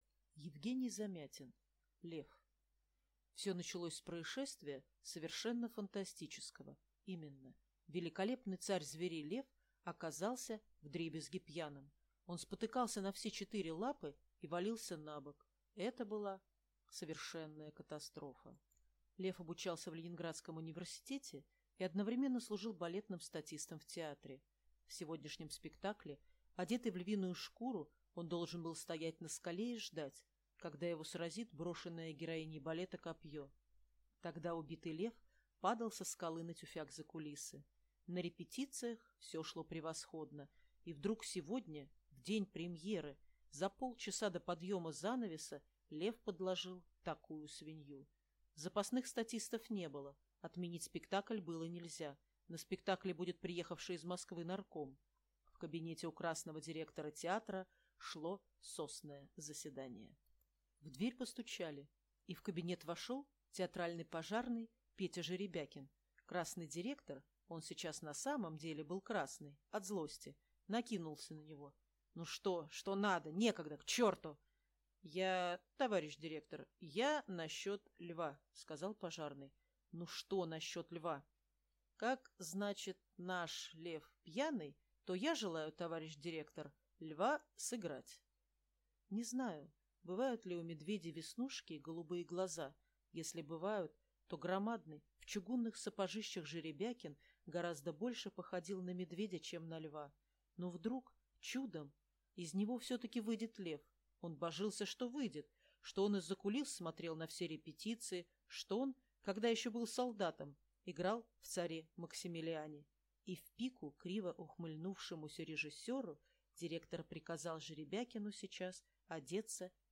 Аудиокнига Лев | Библиотека аудиокниг